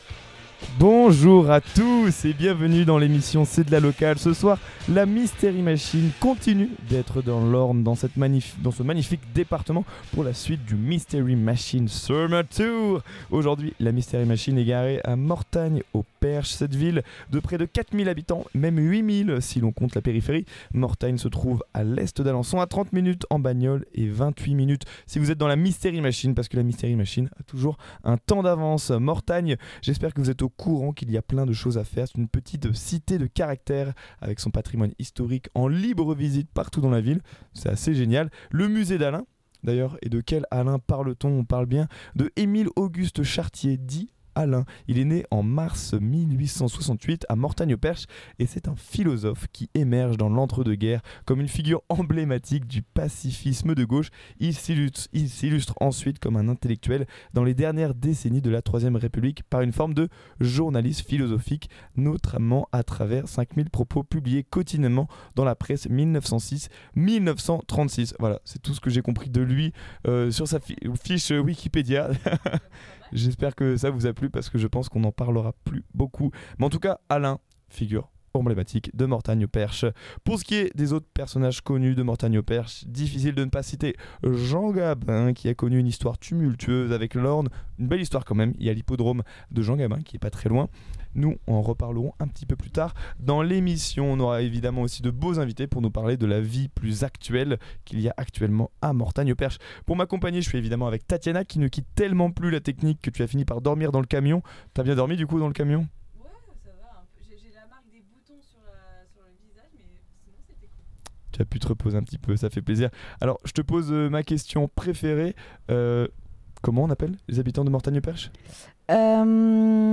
"C'est de la Locale" est une émission quotidienne diffusée en direct de 18 à 19h du lundi au vendredi. On y traite des infos associatives ou culturelles locales.